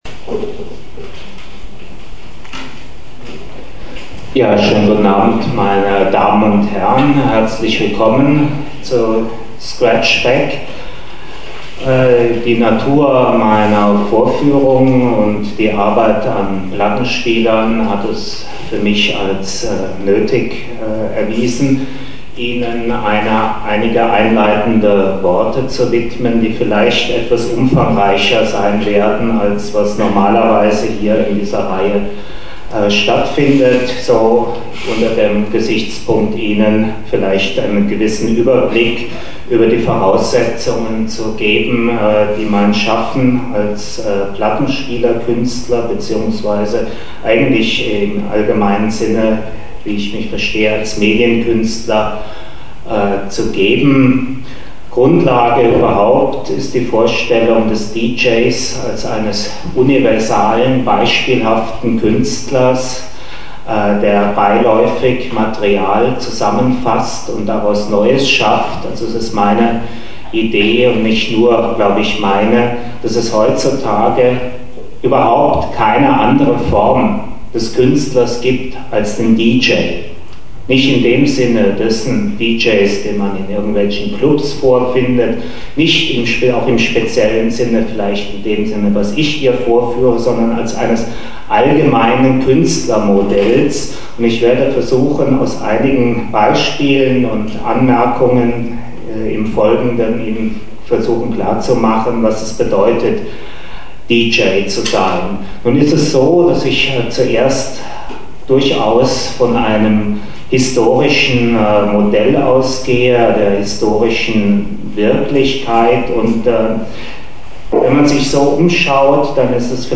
Audio - start missing, but better quality scratch_beck_2005 a
scratch_b_vortrag.mp3